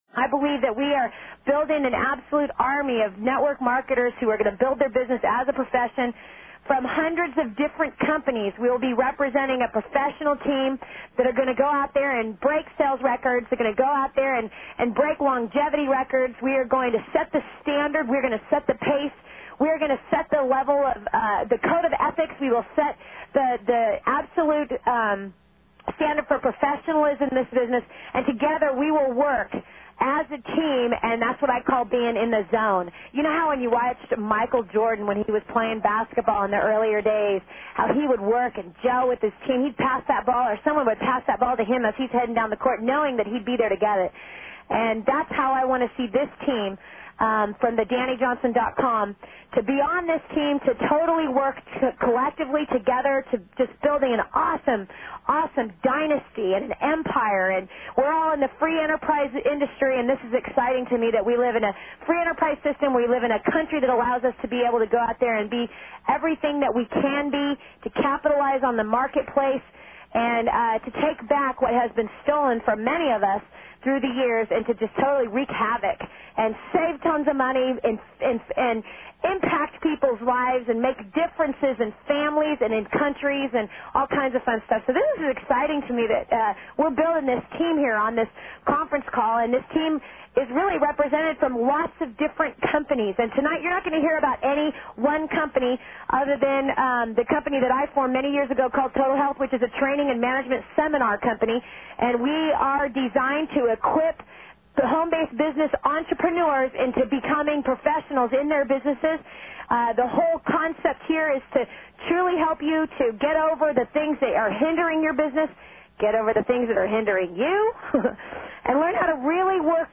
Live Q & A